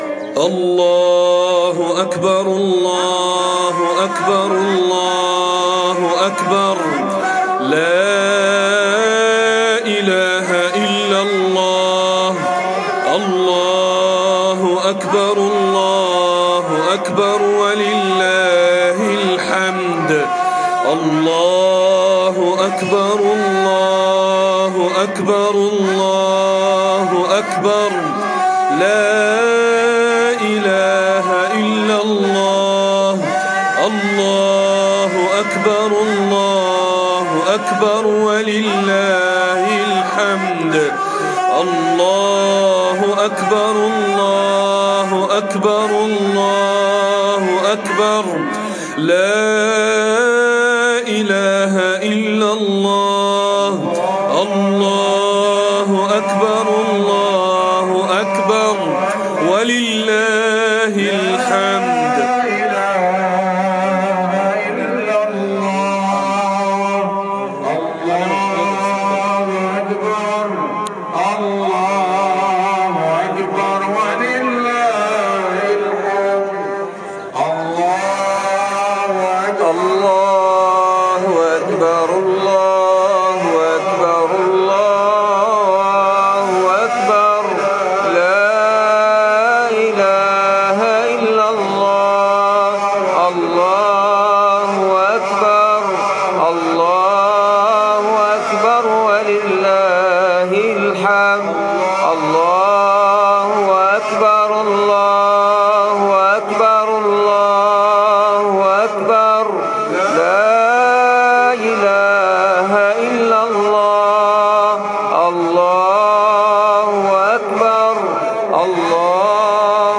خطبة عيد الأضحى المبارك 1446 ه